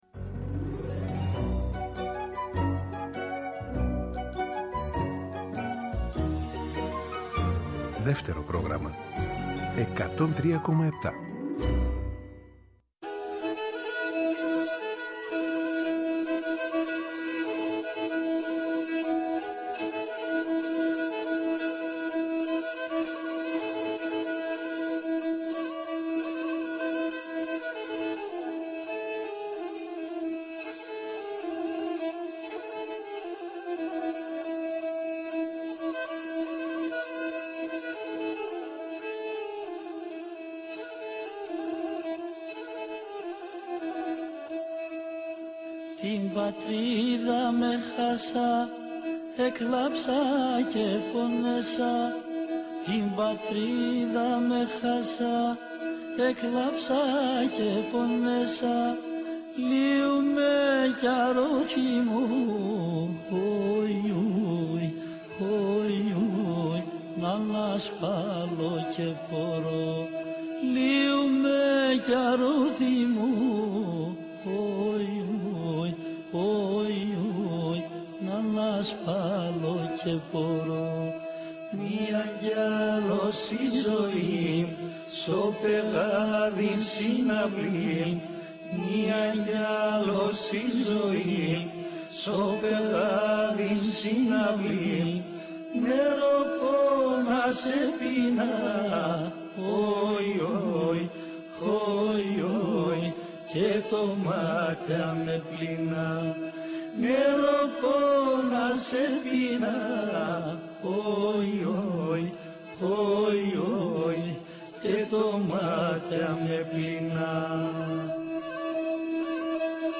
«Έχει η ζωή γυρίσματα» Μία δίωρη ραδιοφωνική περιπλάνηση, τα πρωινά του Σαββατοκύριακου. στο Δεύτερο Πρόγραμμα
Συνεντεύξεις